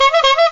proper-clown-short-horn_GhiqOak.mp3